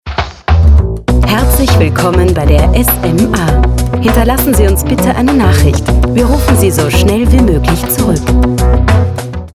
STIMMLAGE: kräftig, voll, warm, markant, sinnlich, zwischen 25-45 J. einsetzbar.
Sprechprobe: Sonstiges (Muttersprache):
female voice over talent german.